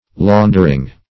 Laundering \Laun"der*ing\, n.